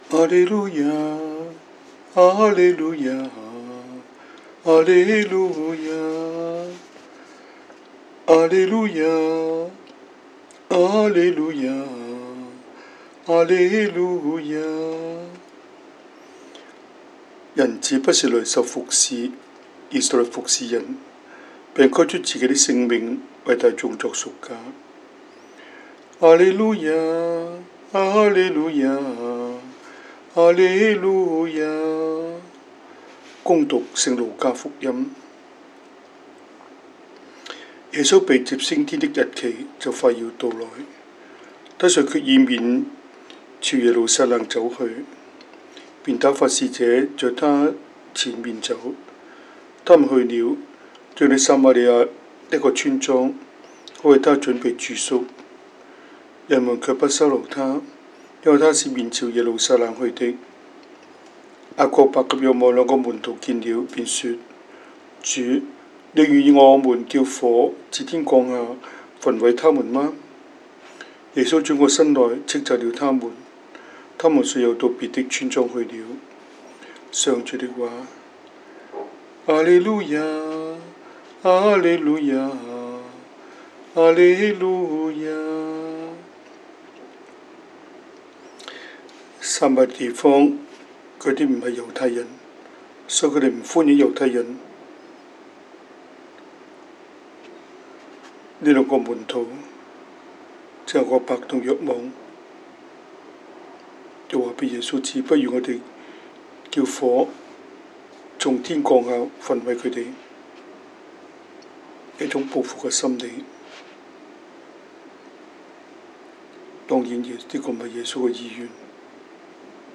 Cantonese Homily, Eng Homily